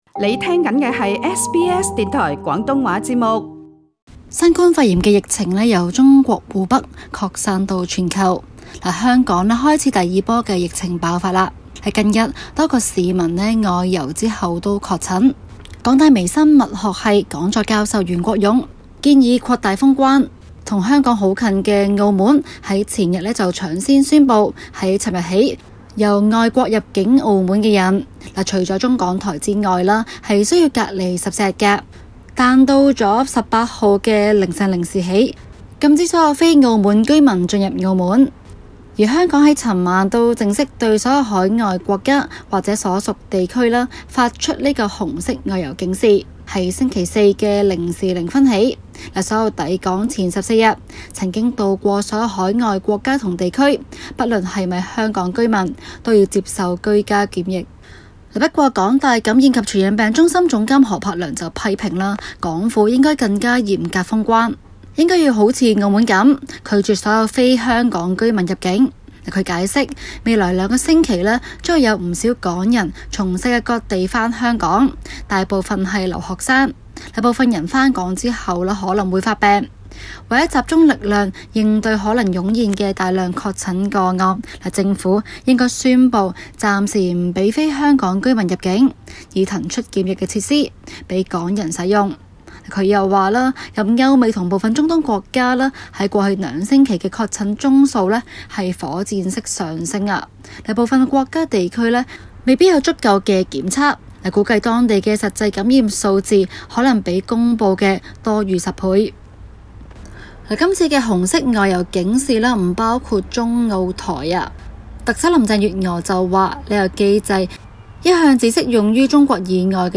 今期 【中港快訊 】環節報導與大家跟進香港出現輸入感染個案劇增趨勢。